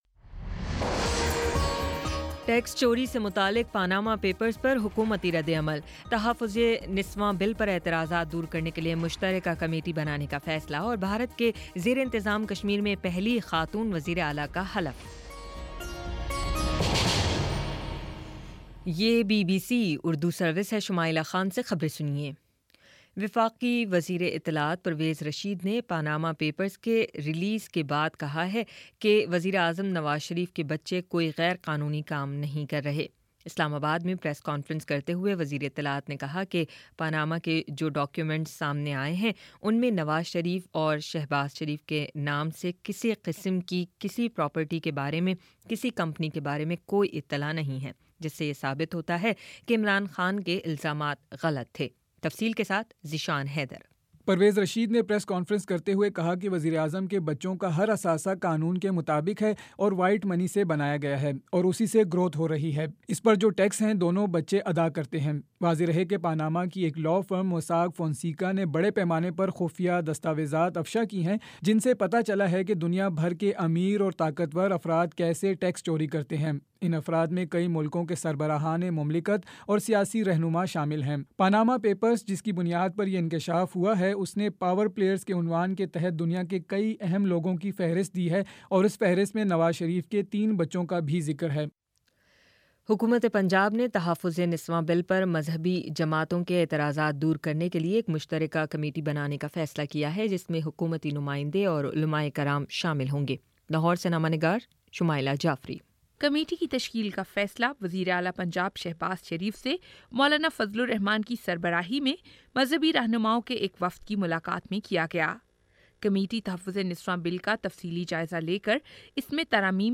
اپریل 04 : شام چھ بجے کا نیوز بُلیٹن